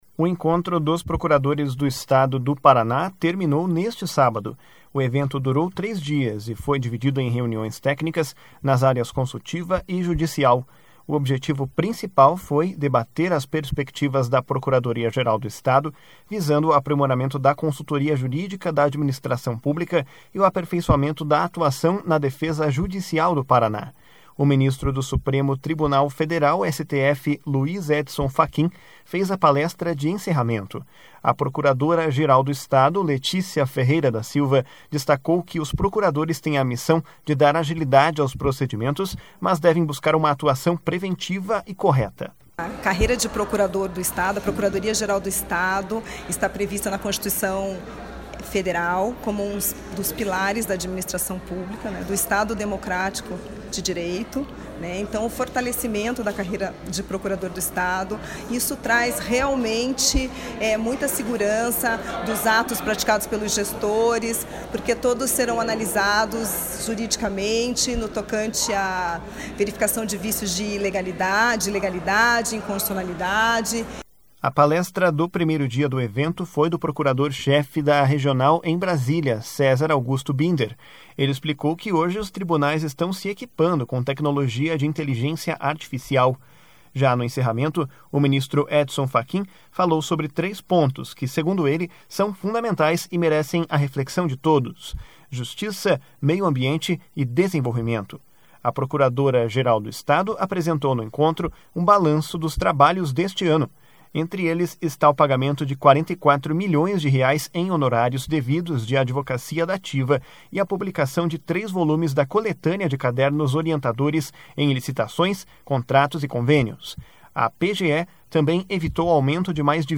A procuradora-geral do Estado, Letícia Ferreira da Silva, destacou que os procuradores têm a missão de dar agilidade aos procedimentos, mas devem buscar uma atuação preventiva e correta. // SONORA LETÍCIA FERREIRA //